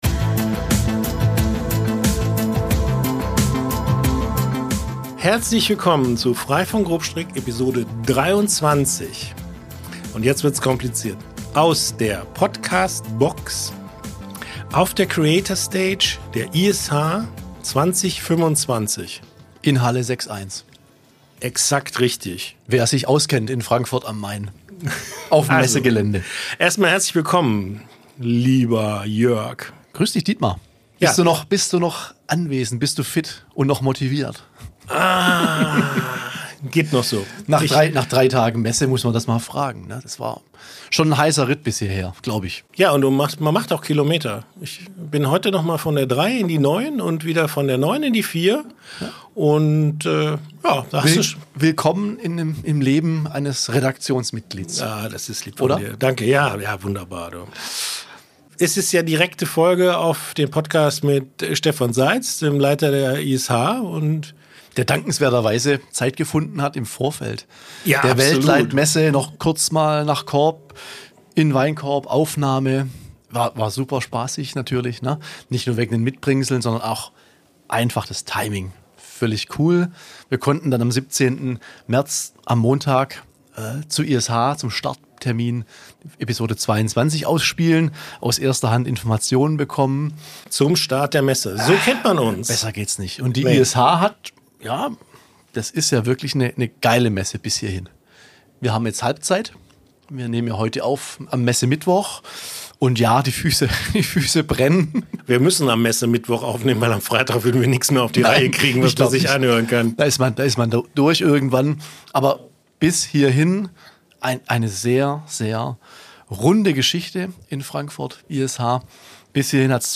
Jetzt sitzen die beiden in der schalldichten Podcastbox auf der Creator Stage in Halle 6.1 und teilen gut gelaunt ihre Messeeindrücke.